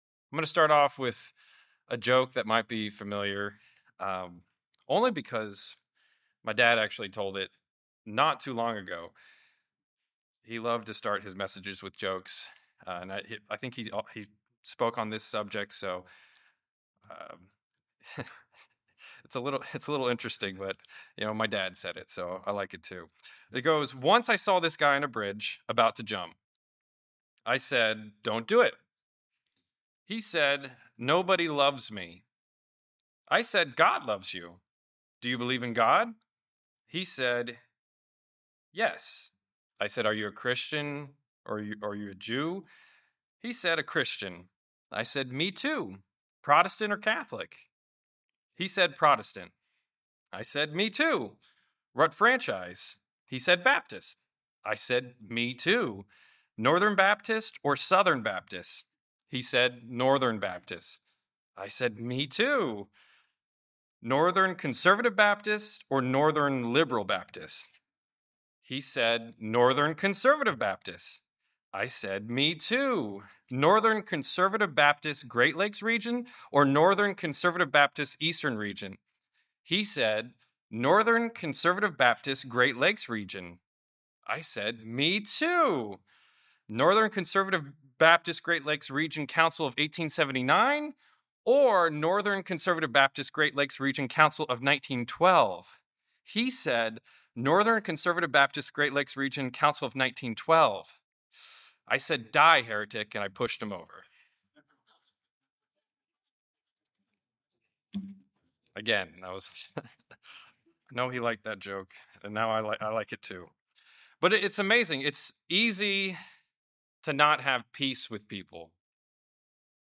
A great challenge that faces us everyday is to be on the same page as those with whom we interact. This sermonette addresses how we can strive to be like-minded.